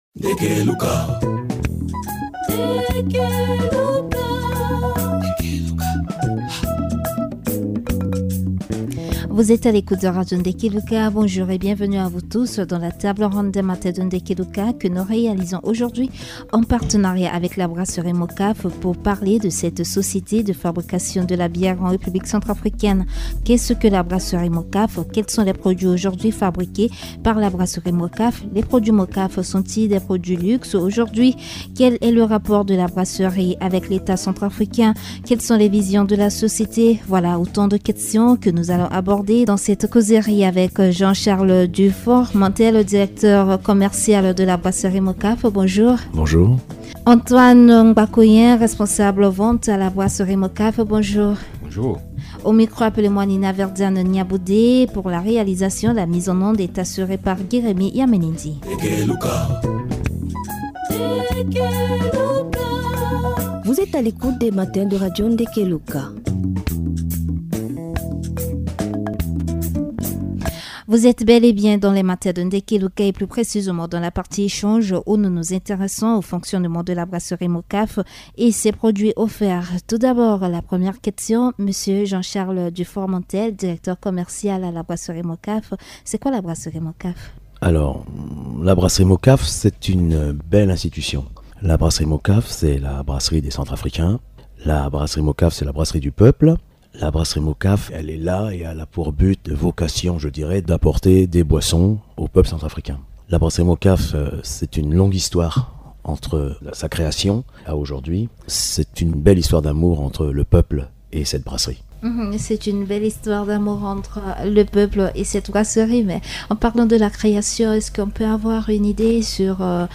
En partenariat avec Radio Ndeke Luka, les responsables de la brasserie MOCAF parlent de cette société. Quels sont les produits fabriqués par cette usine ?